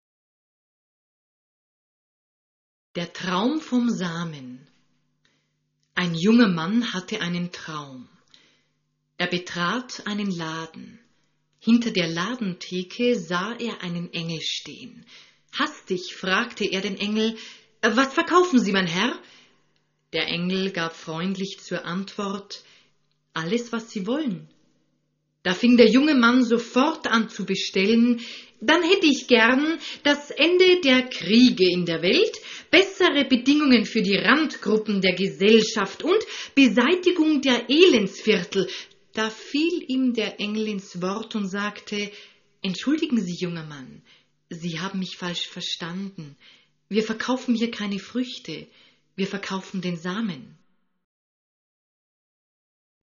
Zwischen den Geschichten genießen Sie meditative Musik.